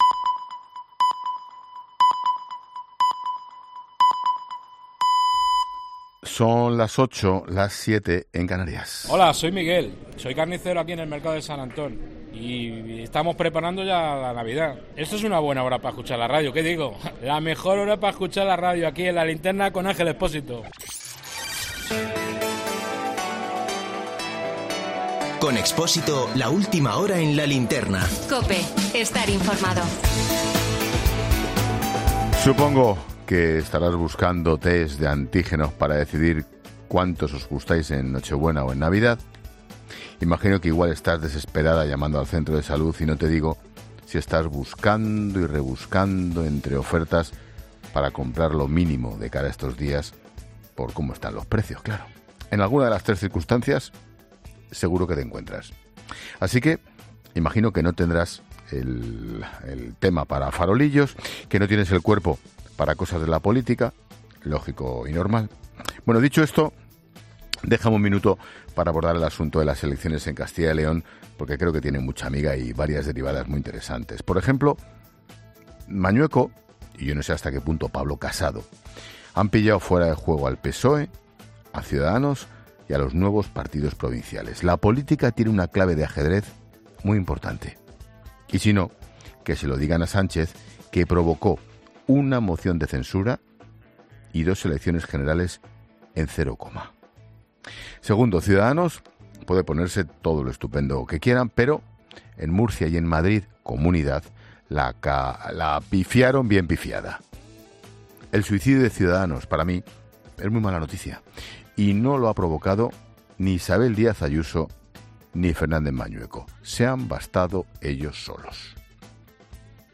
Noticias del día.